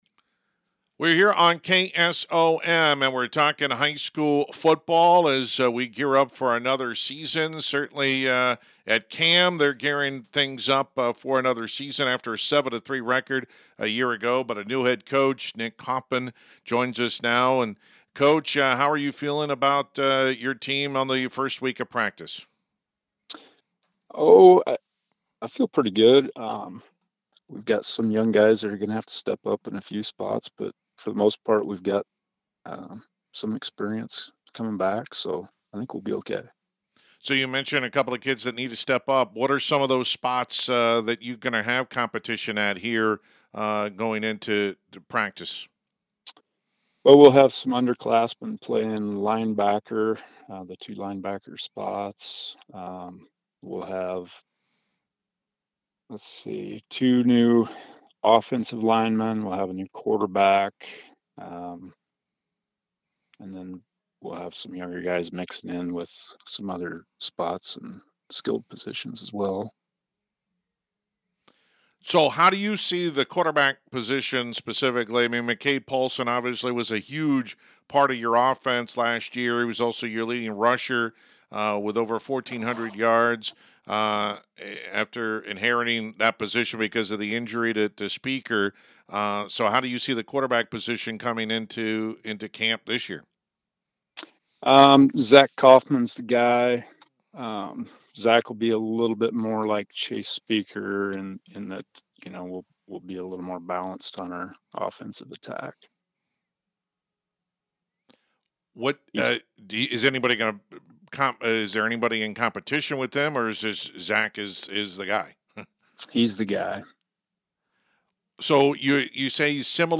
Complete Interview
cam-football-8-13.mp3